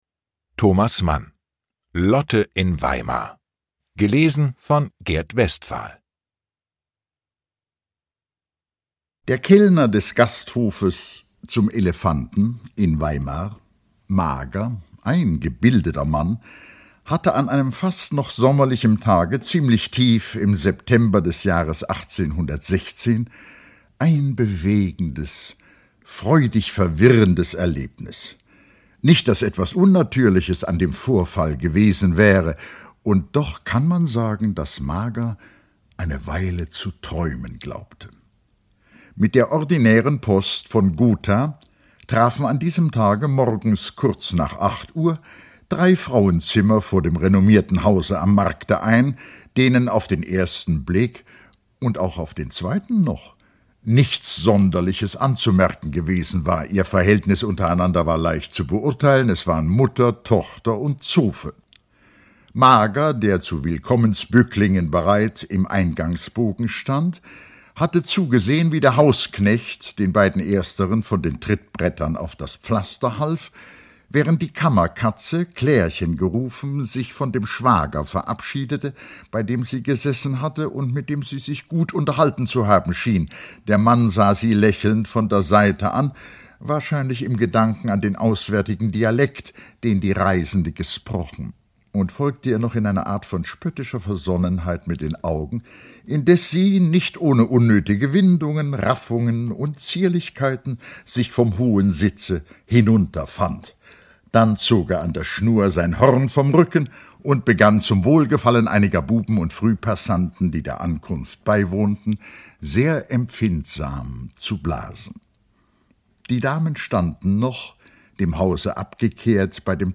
Ungekürzte Lesung mit Gert Westphal (2 mp3-CDs)
Gert Westphal (Sprecher)
Gert Westphal gibt diesem Mann`schen Roman seine klangvolle Stimme.